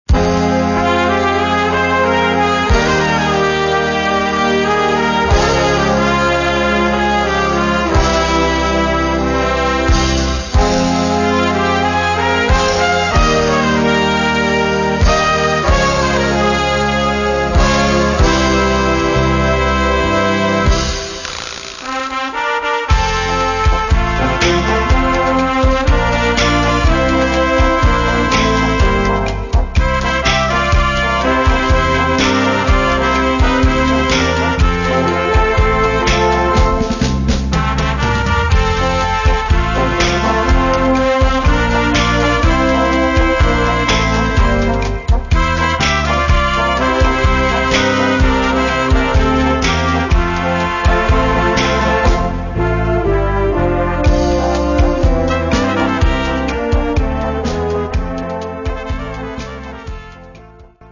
Gattung: Solo für Trompete und Blasorchester
Besetzung: Blasorchester